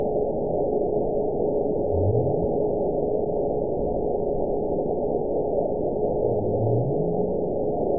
event 922242 date 12/28/24 time 22:08:51 GMT (4 months ago) score 9.59 location TSS-AB04 detected by nrw target species NRW annotations +NRW Spectrogram: Frequency (kHz) vs. Time (s) audio not available .wav